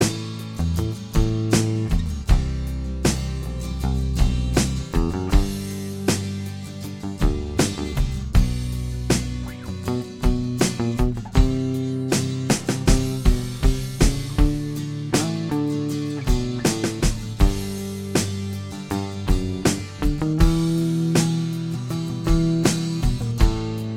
Minus Electric Guitars Pop (1980s) 3:21 Buy £1.50